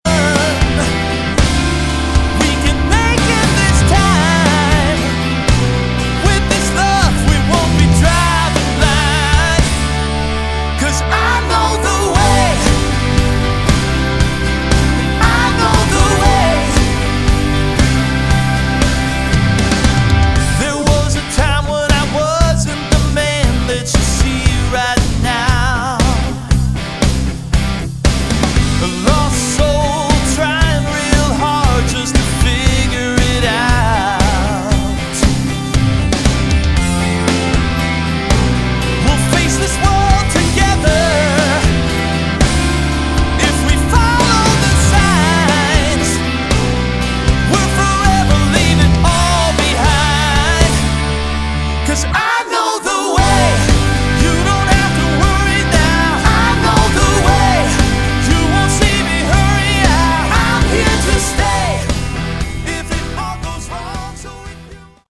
Category: AOR / Melodic Rock
vocals
drums
bass
guitars
keyboards